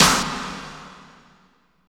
49.05 SNR.wav